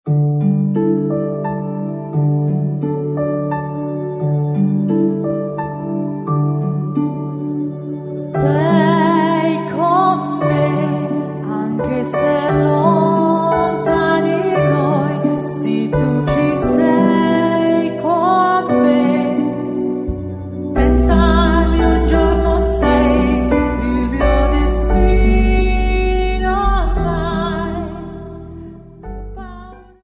Dance: Slow Waltz 29